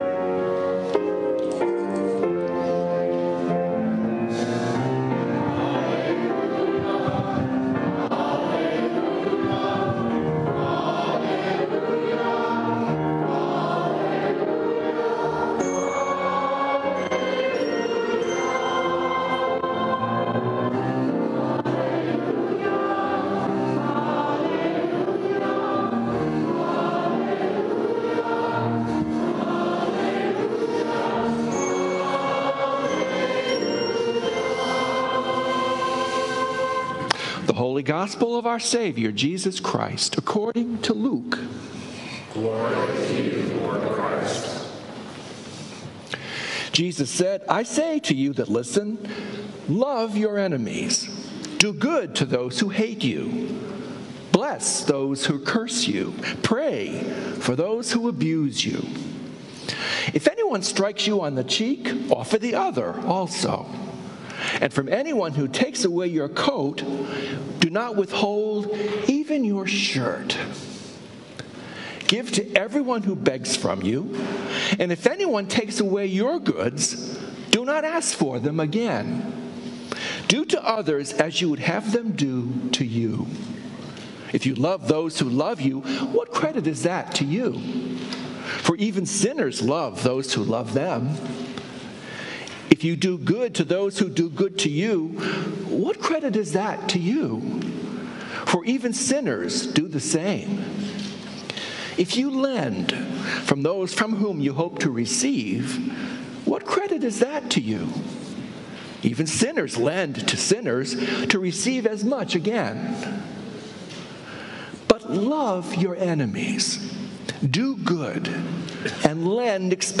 Sermons from St. Columba's in Washington, D.C. Sunday Sermon